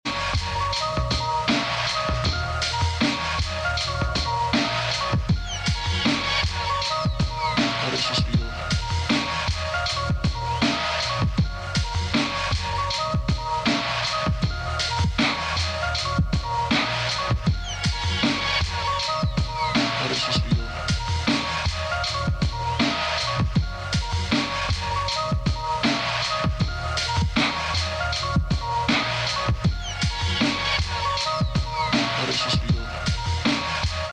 is a perfect blend with the synths